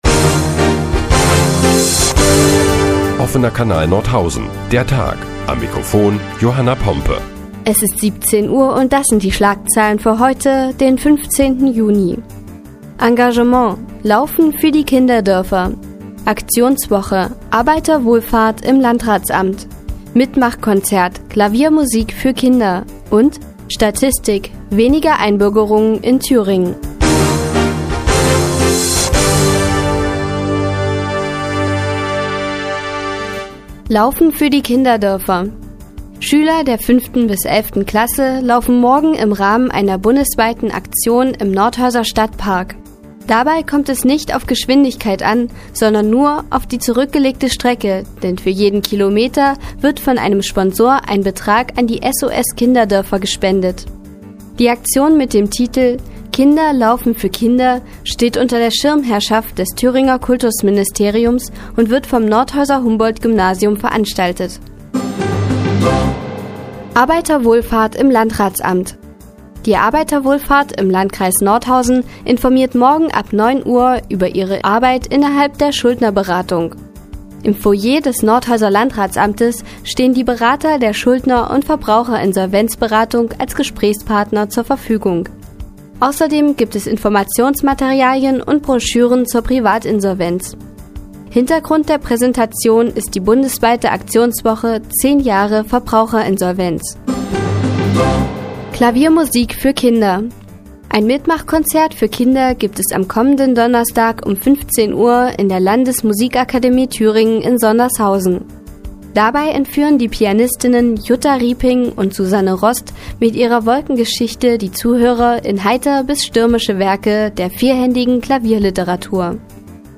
Die tägliche Nachrichtensendung des OKN ist nun auch in der nnz zu hören. Heute geht es unter anderem um die Arbeiterwohlfahrt im Landratsamt und Klaviermusik für Kinder.